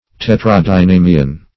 Search Result for " tetradynamian" : The Collaborative International Dictionary of English v.0.48: Tetradynamian \Tet`ra*dy*na"mi*an\, n. (Bot.)
tetradynamian.mp3